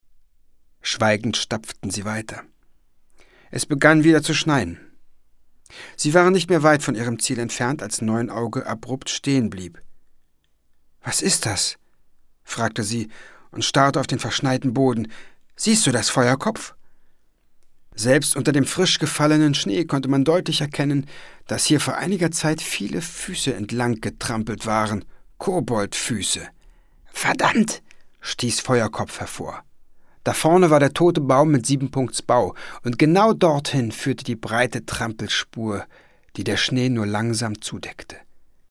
Ravensburger Kein Keks für Kobolde ✔ tiptoi® Hörbuch ab 5 Jahren ✔ Jetzt online herunterladen!